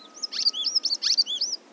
Click here to download at 140 s recording exhibiting extensive mimicry of Dusky Twinspot by Dusky Indigobird (1.6 MB).
indigobirdsonogram3.wav